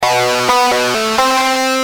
Lead_a5.wav